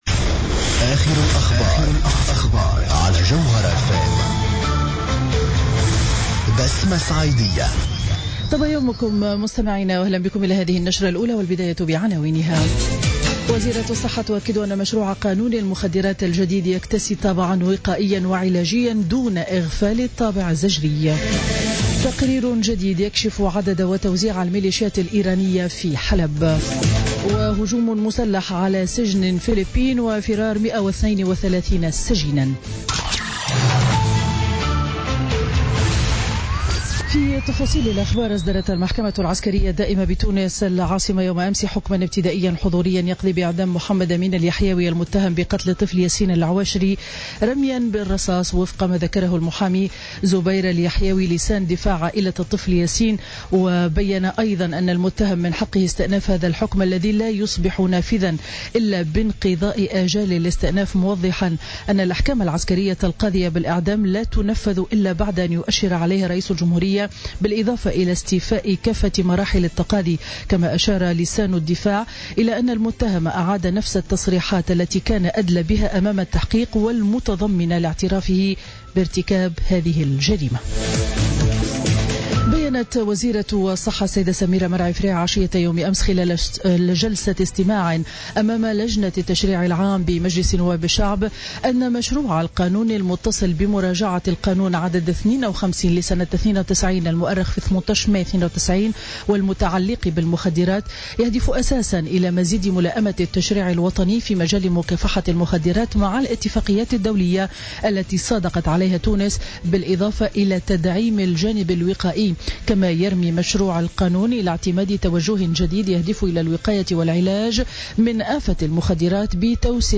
نشرة أخبار السابعة صباحا ليوم الأربعاء 4 جانفي 2017